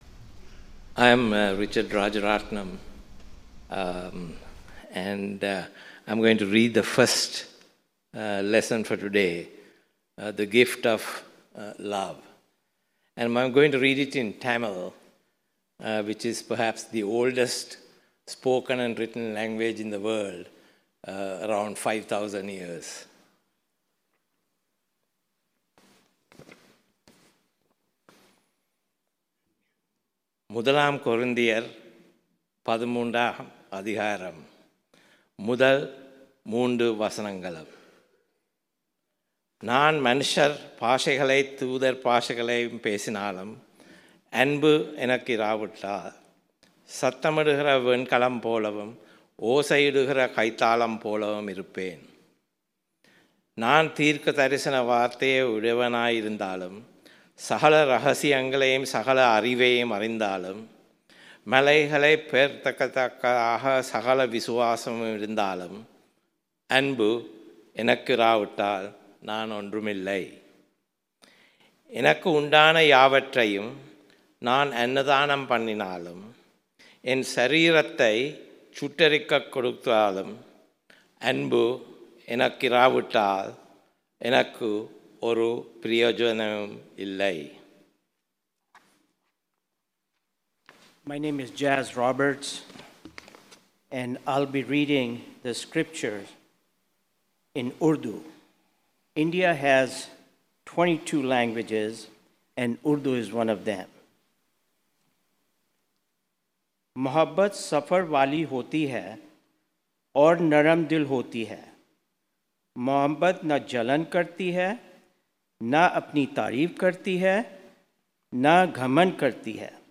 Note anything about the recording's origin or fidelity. Seventeenth Sunday after Pentecost sermon